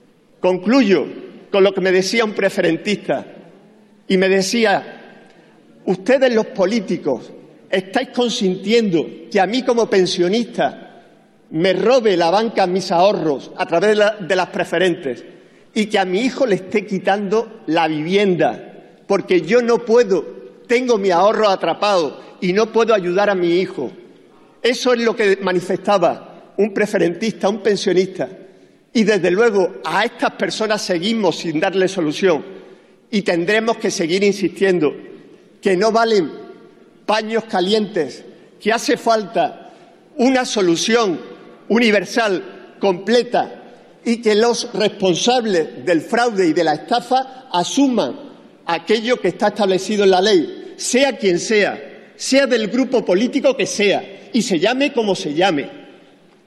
Fragmento de la intervención de Antonio Hurtado en el pleno del 24/06/2014 en apoyo a una iniciativa del Grupo Mixto para que recuperen todo su dinero los preferentistas sin ahorradores sin experiencia financiera